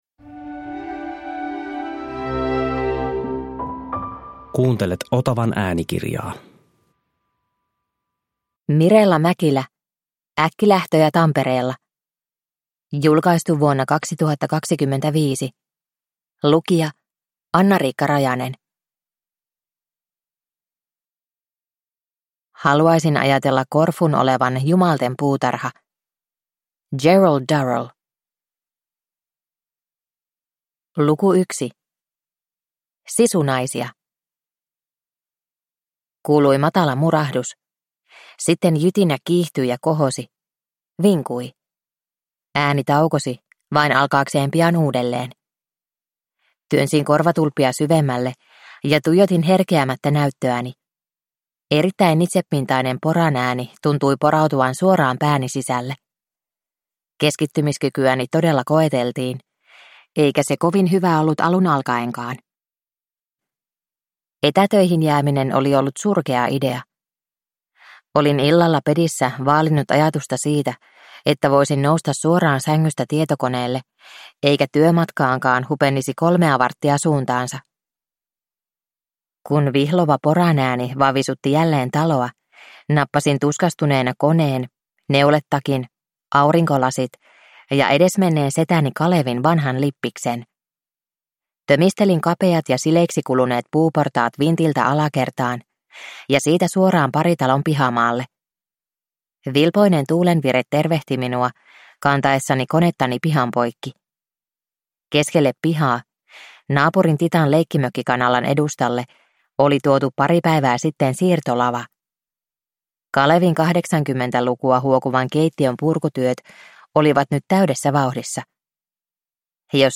Äkkilähtöjä Tampereella – Ljudbok